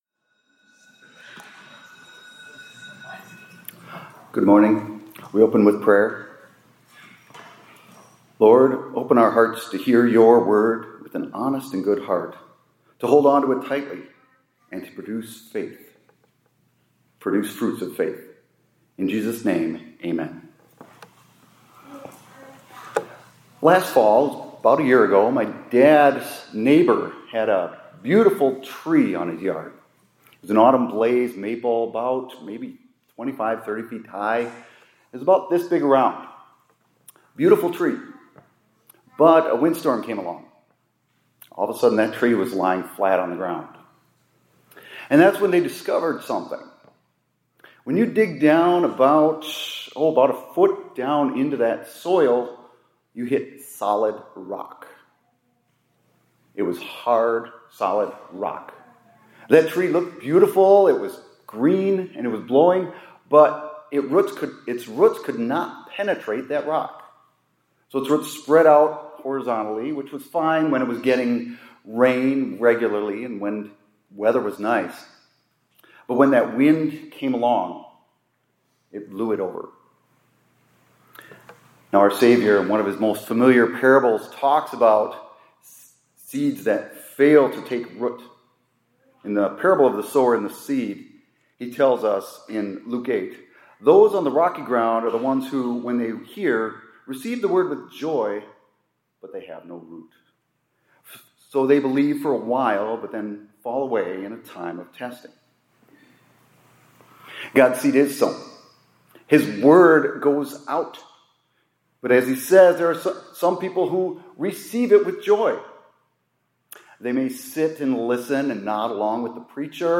2025-08-29 ILC Chapel — Christ Gives Us Depth of…